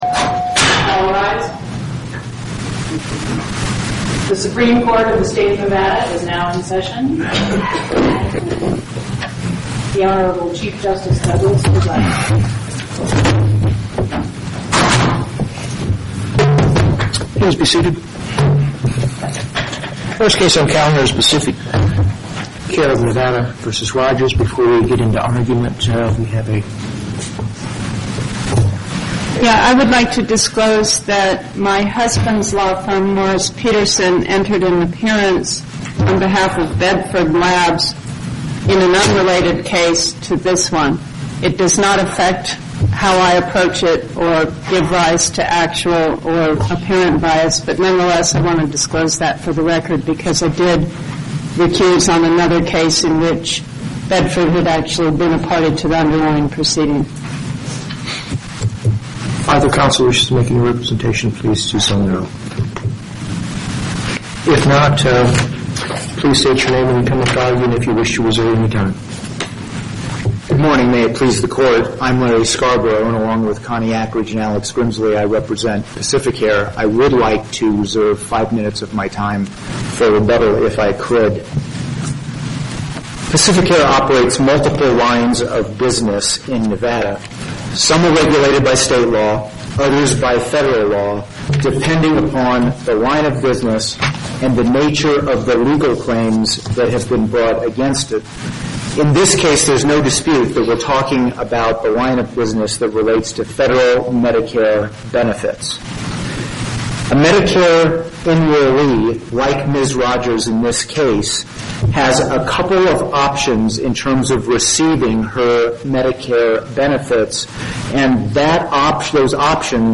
Location: Carson City Before the En Banc Court, Chief Justice Douglas Presiding